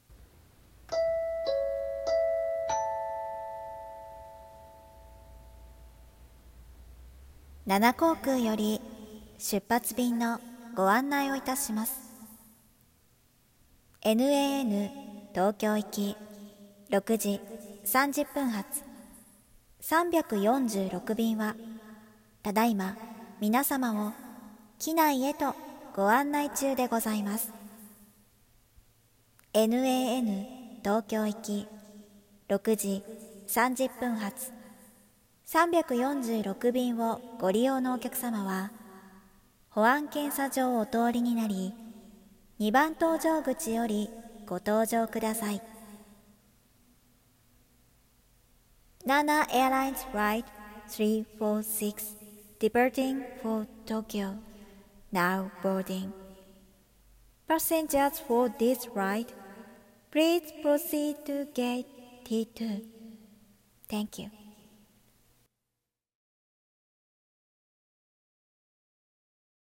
【声劇】空港アナウンス(ピンポンパンポン付き)